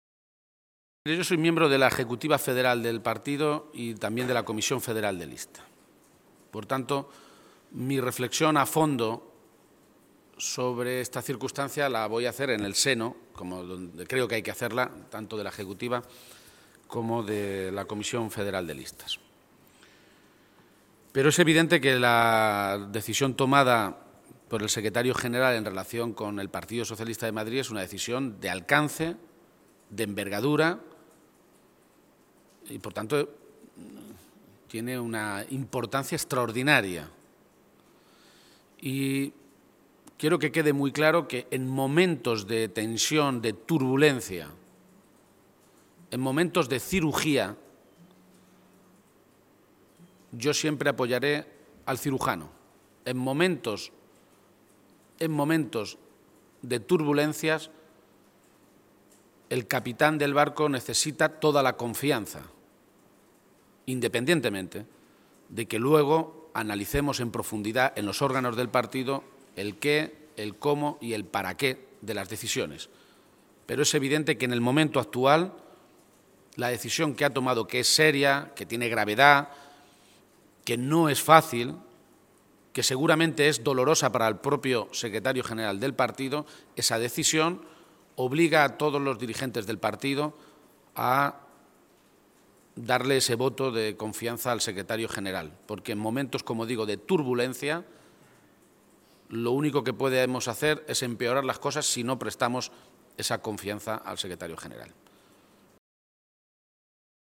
García-Page se pronunciaba de esta manera esta mañana, en Toledo, a preguntas de los medios de comunicación en una comparecencia en la que se pedía su opinión sobe la decisión de la dirección federal de disolver los órganos de dirección del partido en Madrid.
Cortes de audio de la rueda de prensa